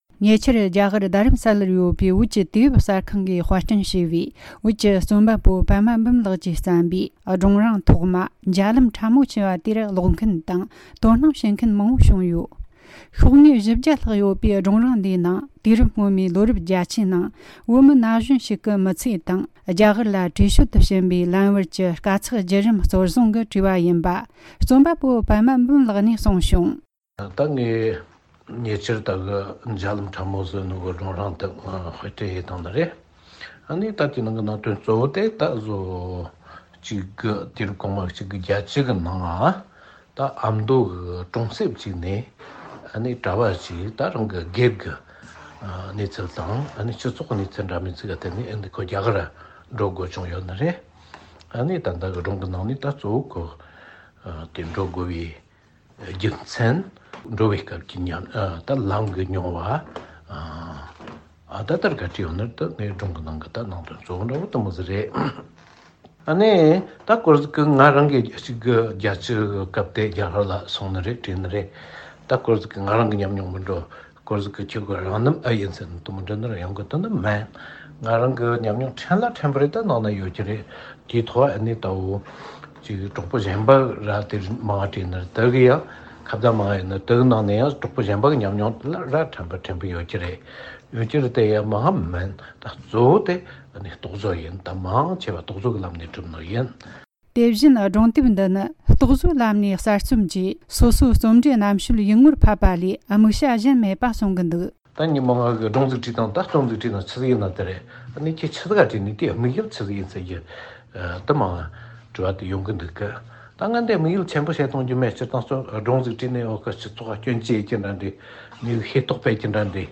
འབྲེལ་ཡོད་མི་སྣར་བཅར་འདྲི་བྱས་བར་གསན་རོགས་གནོངས།།